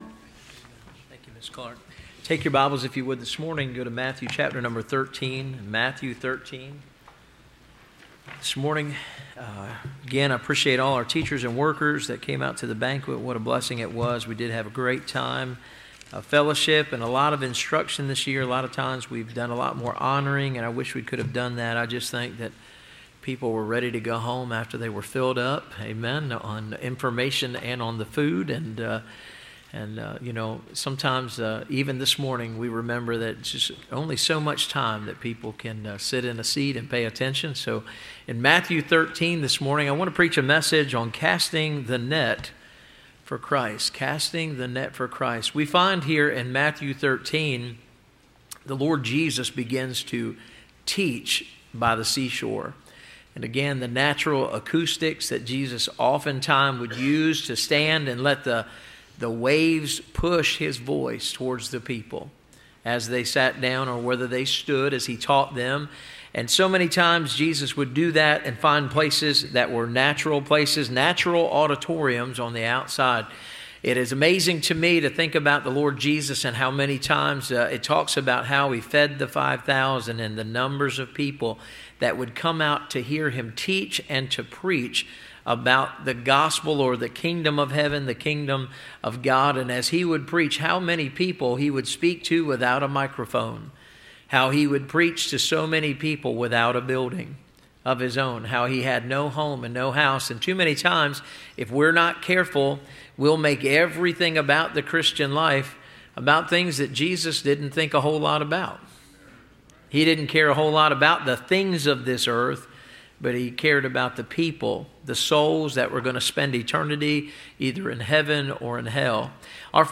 Listen to Message
Service Type: Sunday Morning